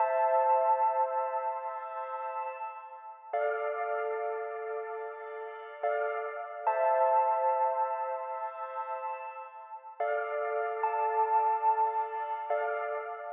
Watch Out_Pluck.wav